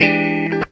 GTR 38 EM.wav